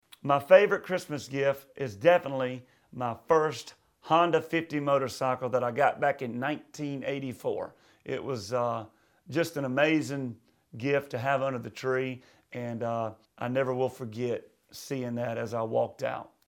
Audio / LUKE BRYAN REVEALS HIS FAVORITE CHRISTMAS GIFT AS A CHILD.